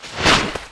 • 声道 單聲道 (1ch)